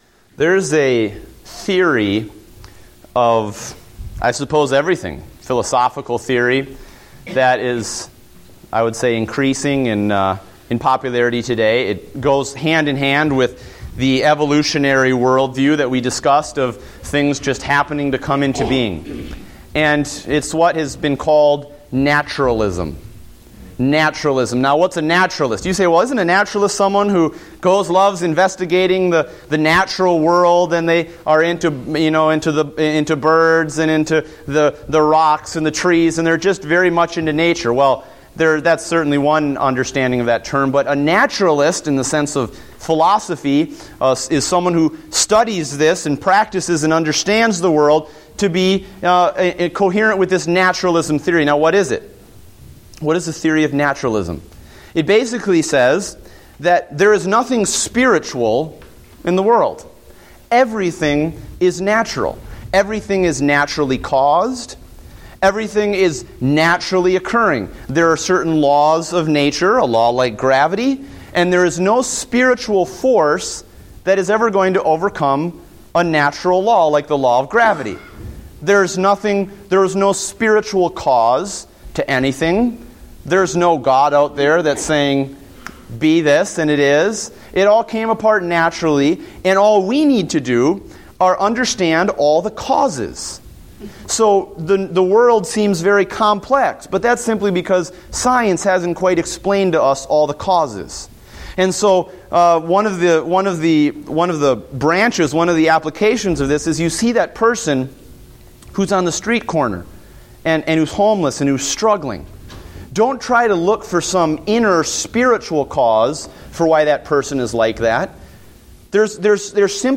Date: October 5, 2014 (Adult Sunday School)